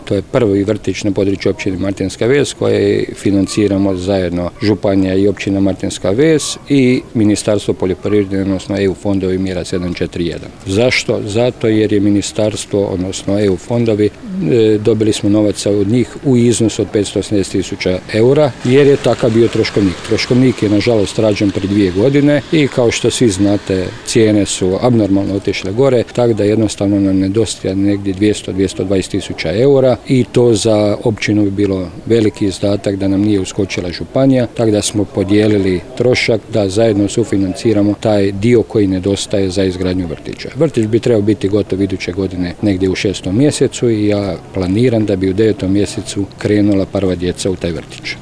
Više o tome, načelnik Stjepan Ivoš